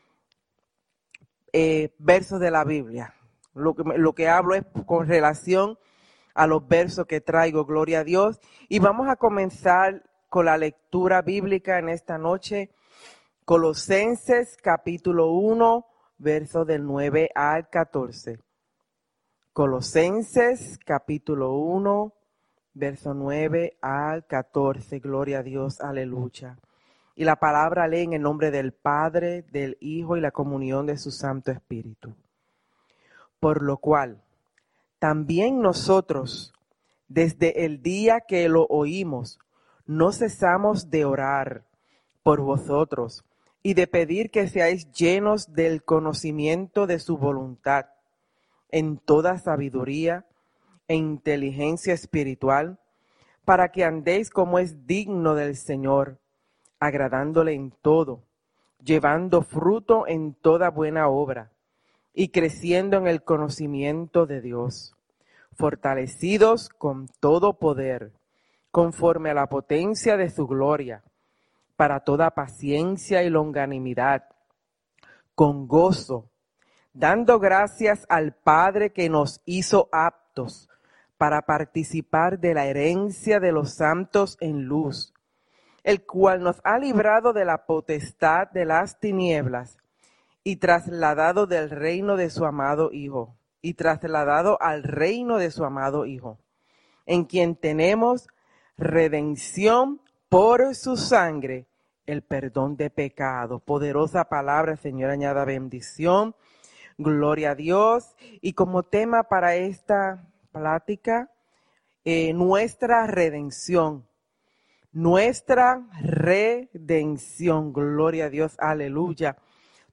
Nuestra Redención | Predica